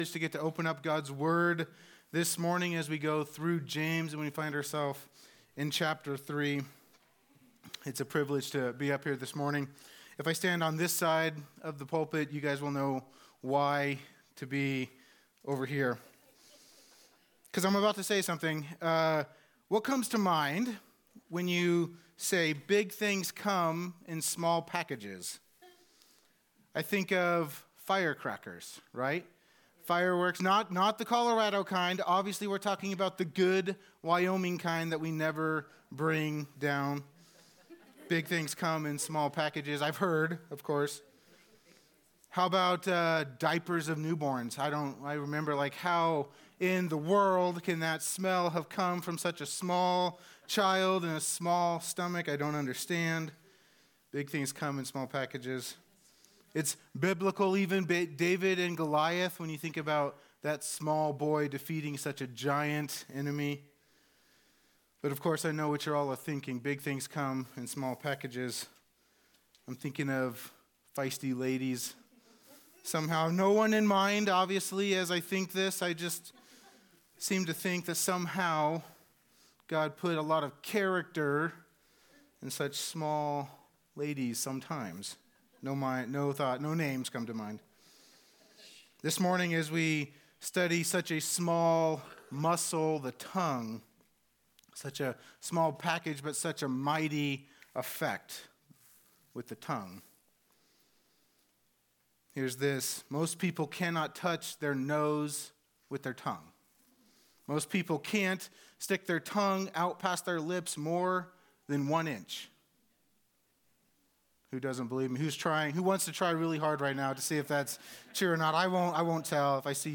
Women’s Breakfast 1/12 | High Plains Harvest Church